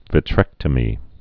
(vĭ-trĕktə-mē)